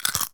pgs/Assets/Audio/Comedy_Cartoon/comedy_bite_chew_06.wav at master
comedy_bite_chew_06.wav